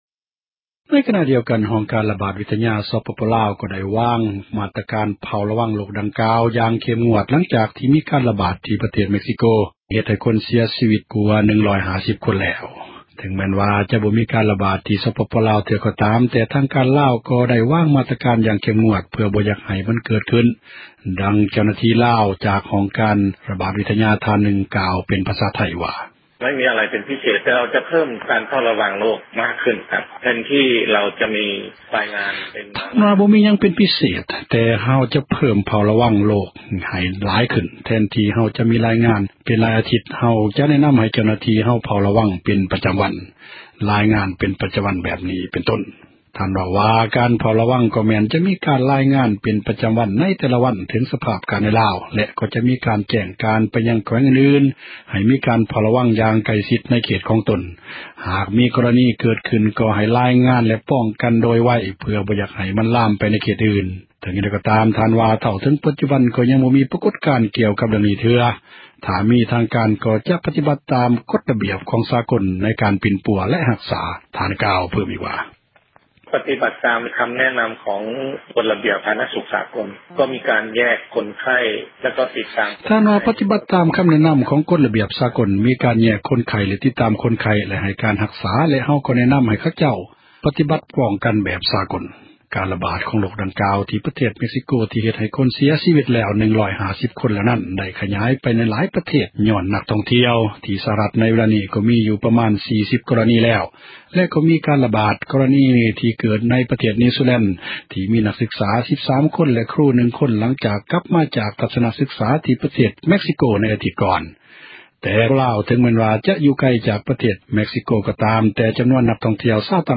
ລາວ ເຝົ້າຣະວັງ ໂຣຄໄຂ້ຫວັດຫມູ — ຂ່າວລາວ ວິທຍຸເອເຊັຽເສຣີ ພາສາລາວ